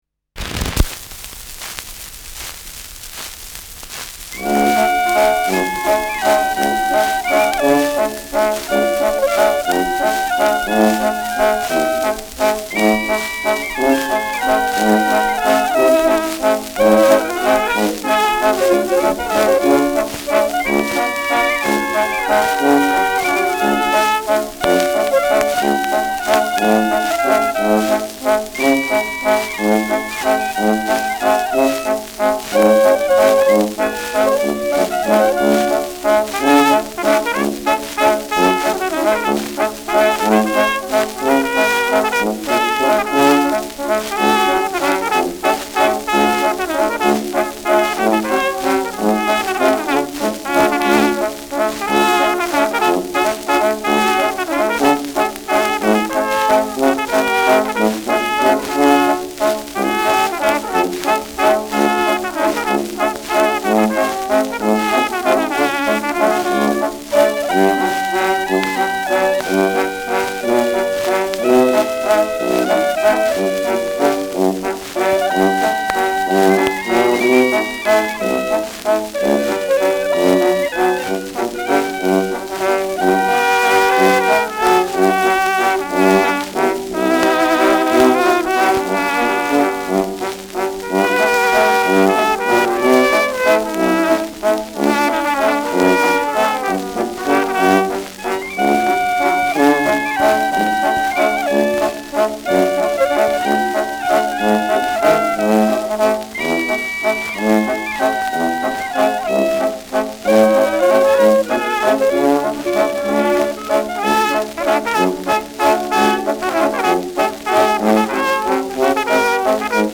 Schellackplatte
Durchgehend leichtes Nadelgeräusch durch Tonarmspringen : Leiern : Vereinzelt leichtes Knacken